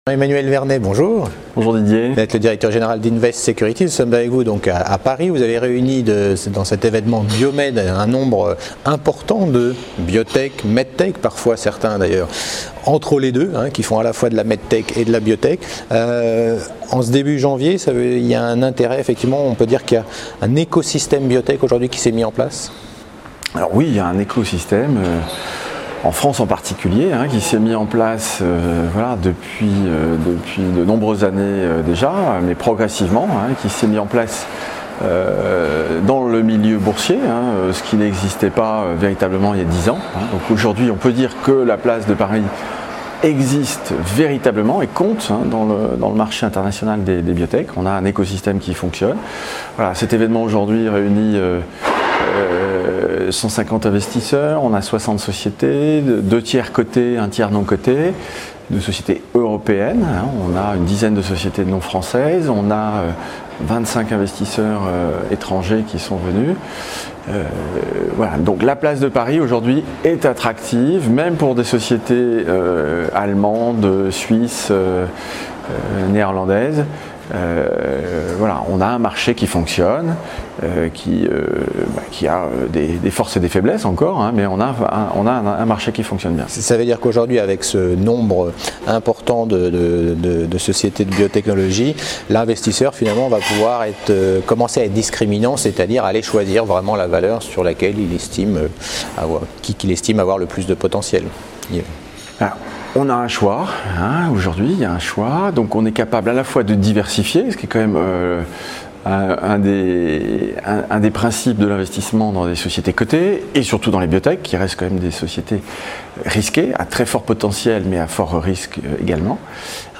Category: L'INTERVIEW